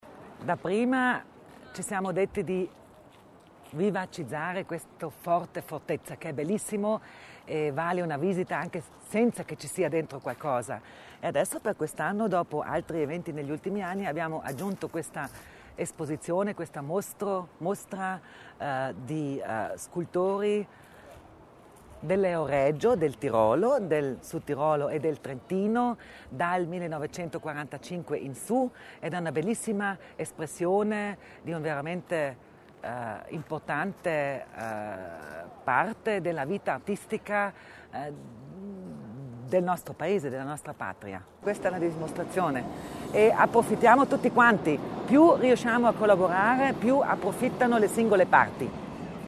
L'Assessora Kasslatter Mur sul valore del progetto
L'assessora Kasslatter Mur nel corso dell'inaugurazione della mostra Si è svolta questo pomeriggio nel Forte di Fortezza l’inaugurazione della mostra di scultura " Figura " alla presenza del presidente della Provincia, Luis Durnwalder, dell'assessora Sabina Kasslatter Mur e dell'assessore della Provincia di Trento, Franco Panizza.